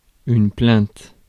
Ääntäminen
Ääntäminen France: IPA: [plɛ̃t] Haettu sana löytyi näillä lähdekielillä: ranska Käännös Substantiivit 1. тъжба 2. жалба Muut/tuntemattomat 3. оплакване {n} 4. недоволство {n} (nedovólstvo) Suku: f .